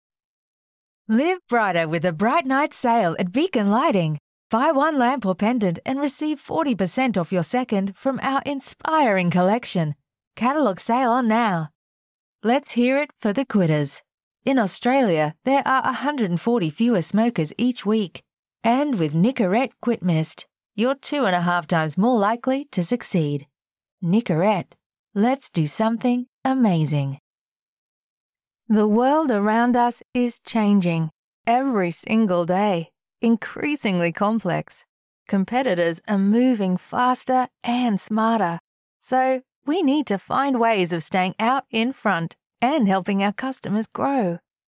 Female
Radio Commercials
Australian Demo Reel
Words that describe my voice are Conversational, Believable, Engaging.
All our voice actors have professional broadcast quality recording studios.